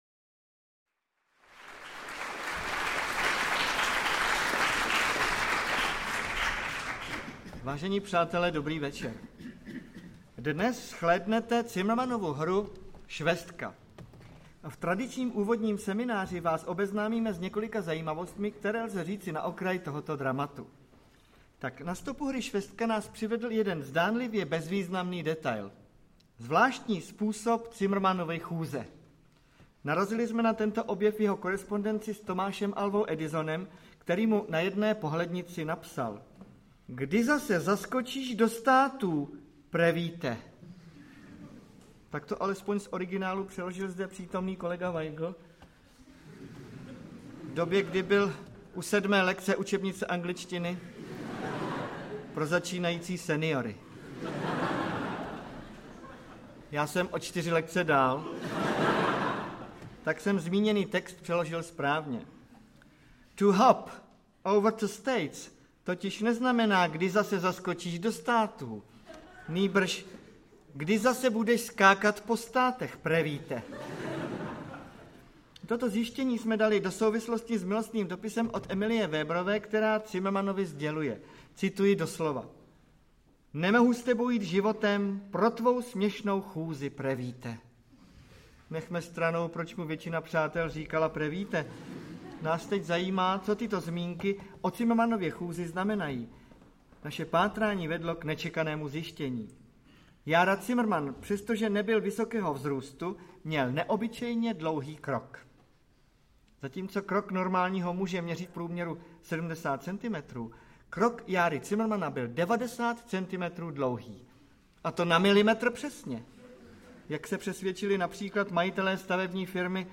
Interpreti:  Ladislav Smoljak, Zdeněk Svěrák